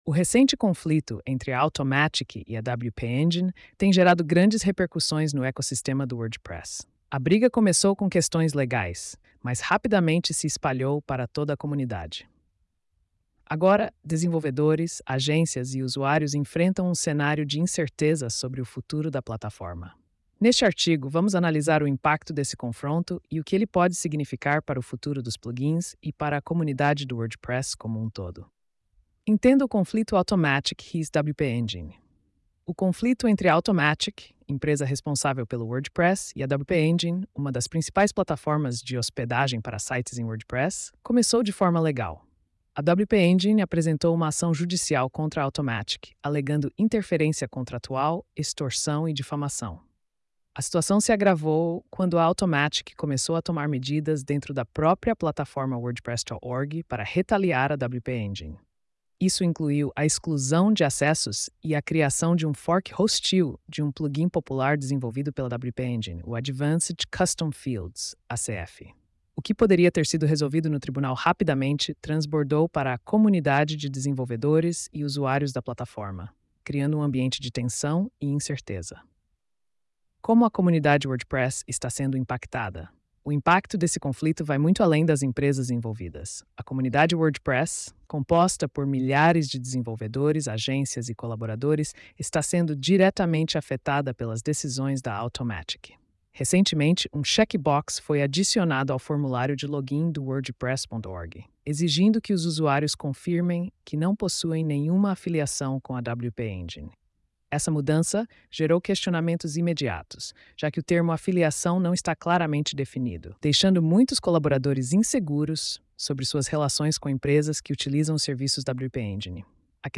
post-2574-tts.mp3